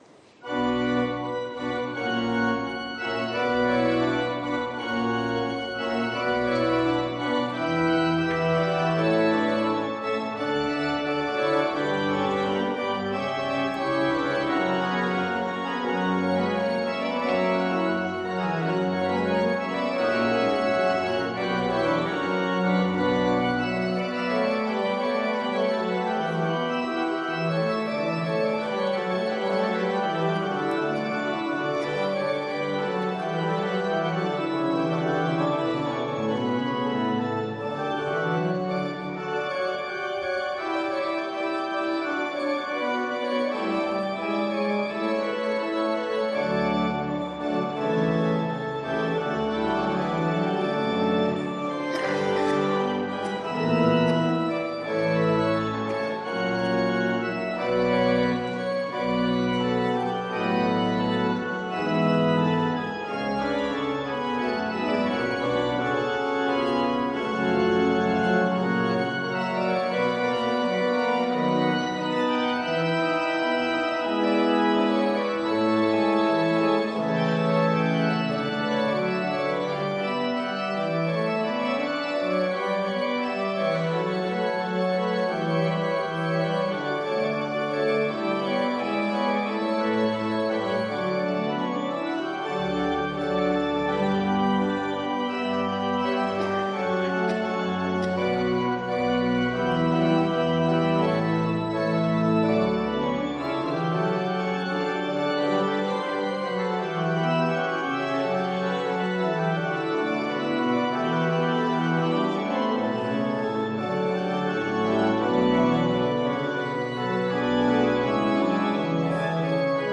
Gottesdienst am 15.05.2022
Audiomitschnitt unseres Gottesdienstes vom Sonntag Kantate 2022.